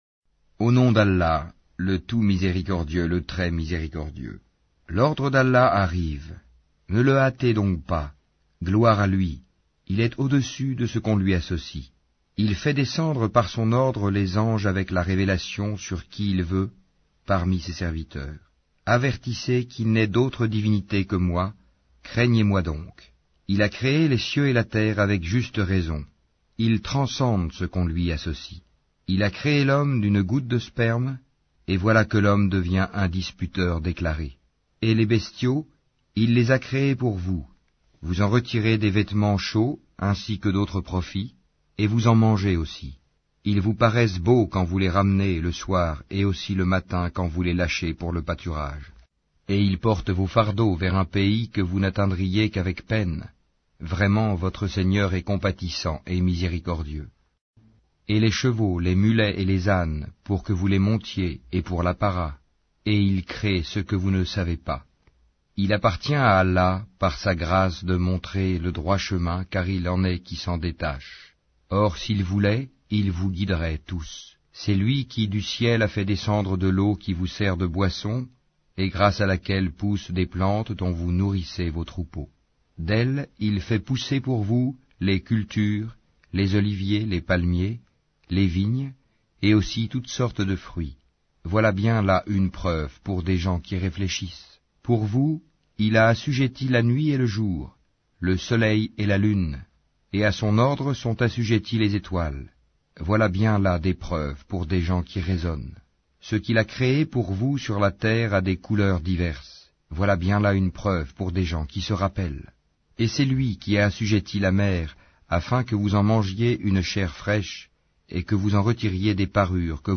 An-Nahl Lecture audio